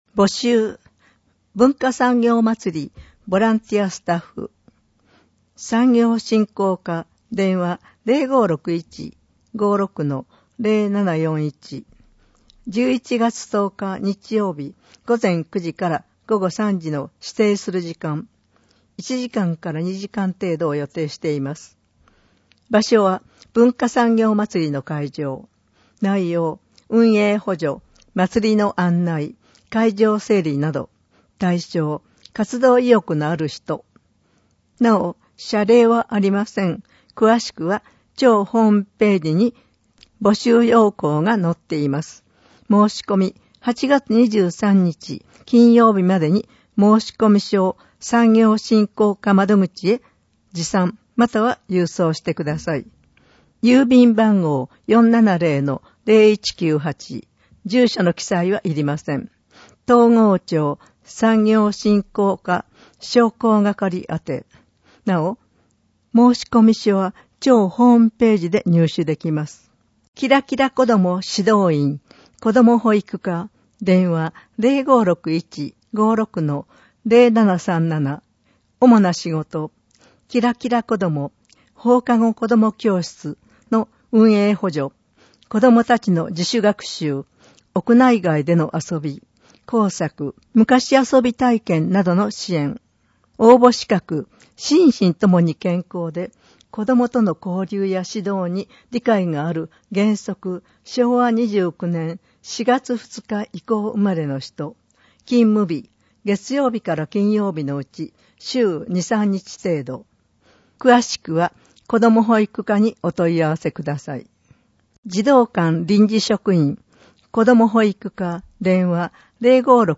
広報とうごう音訳版（2019年8月号）